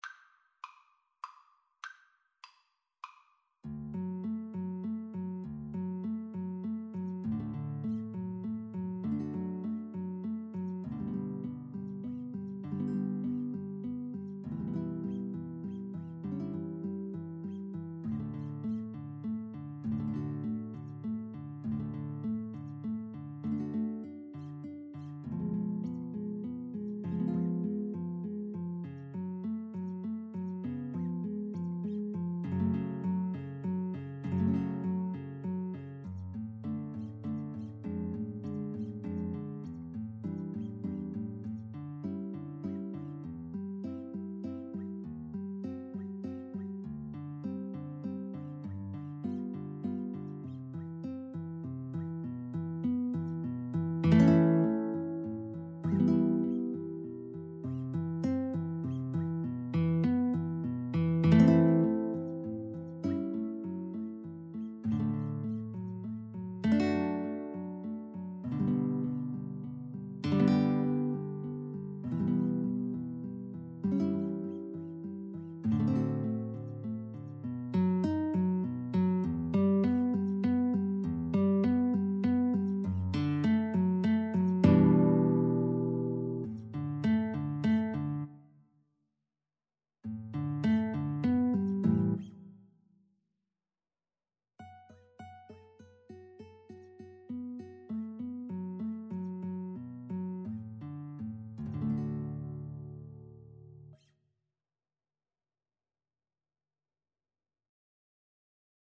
3/4 (View more 3/4 Music)
Cantabile
Classical (View more Classical Guitar-Cello Duet Music)